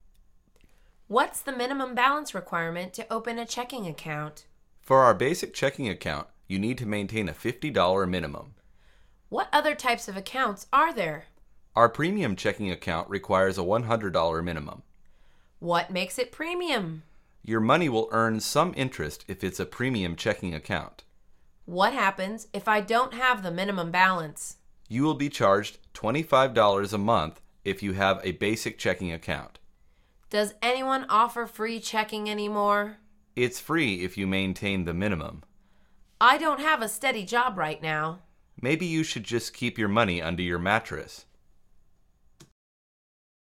مجموعه مکالمات ساده و آسان انگلیسی: حداقل نیاز موجودی